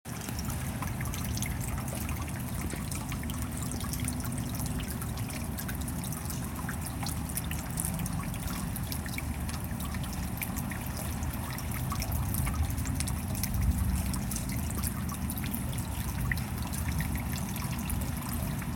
rain